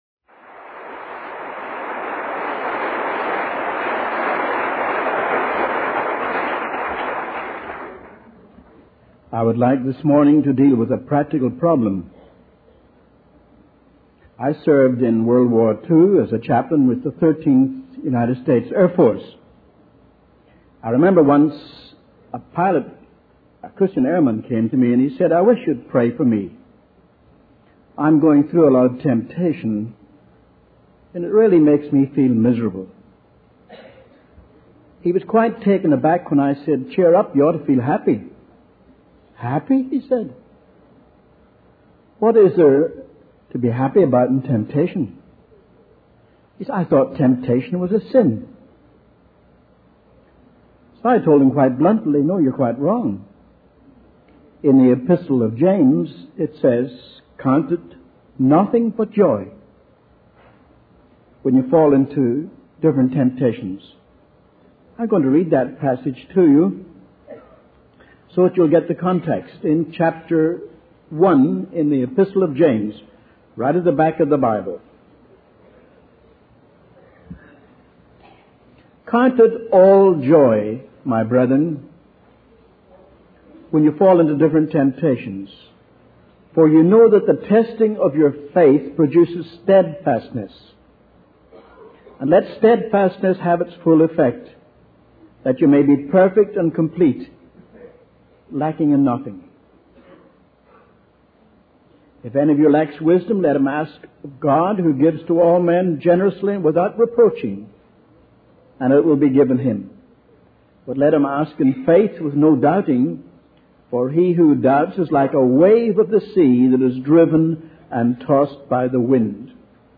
In this sermon, the speaker emphasizes the importance of being vigilant against temptation. He compares temptation to a fire that can quickly spread if not contained. The speaker quotes scripture to explain how desire leads to sin, and sin ultimately leads to death.